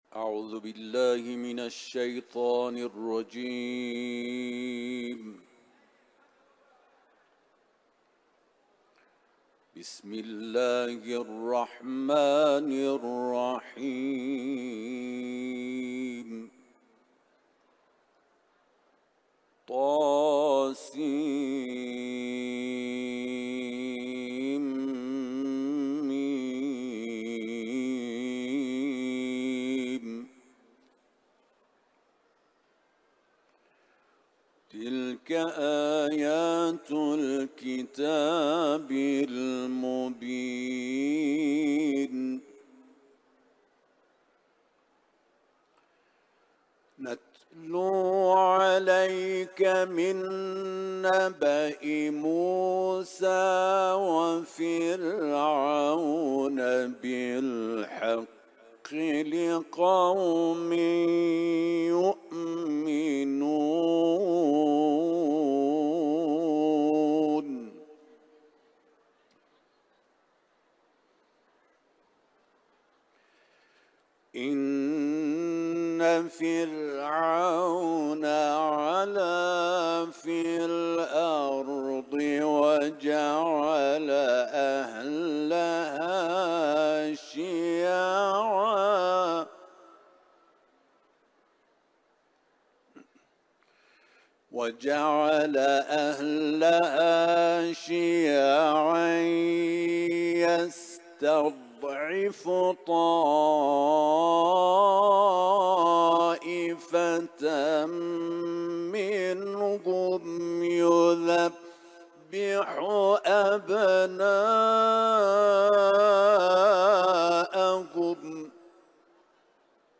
Etiketler: İranlı kâri ، kuran ، tilavet